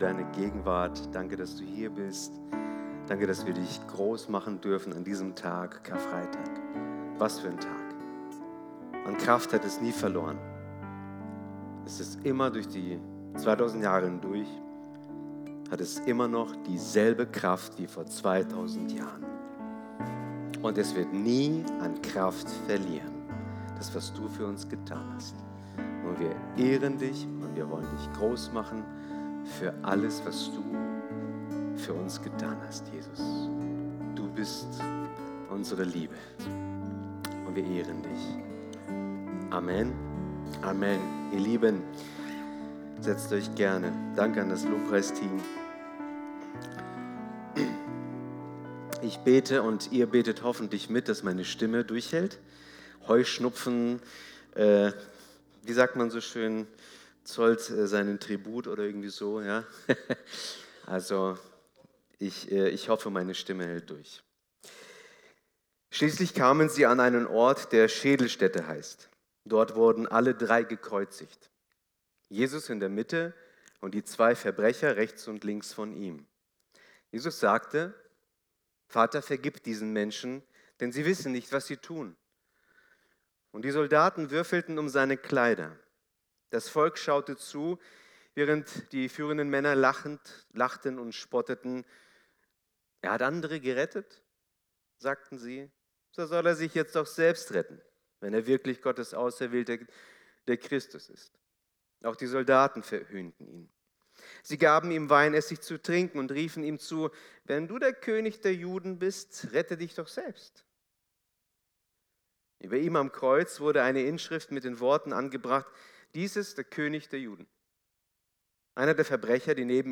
Wöchentliche Predigten des Christlichen Gemeindezentrums Albershausen